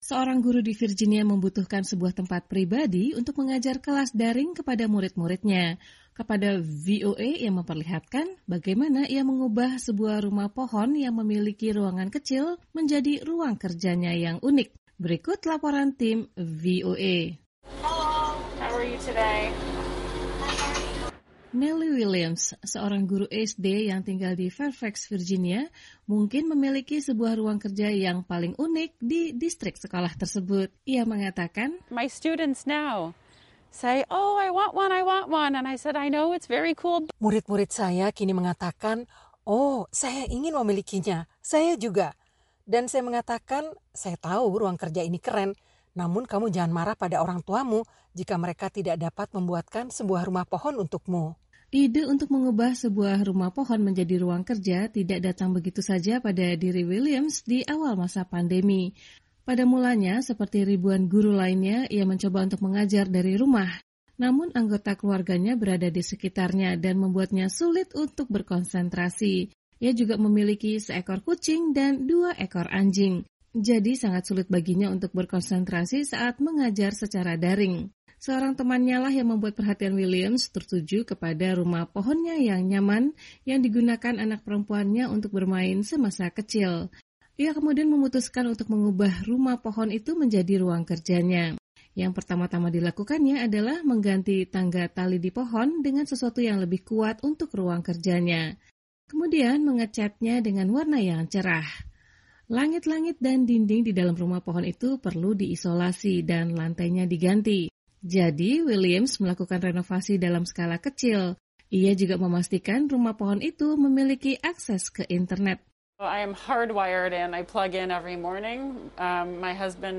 Seorang guru di Virginia membutuhkan sebuahtempat pribadi untuk mengajar kelas daring kepada murid-muridnya. Kepada VOA, iamemperlihatkan bagaimana ia mengubah sebuahrumah pohon yang memiliki ruangan kecil menjadiruang kerjanya yang unik. Berikut laporan timVOA.